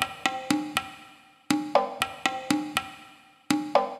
120_perc_3.wav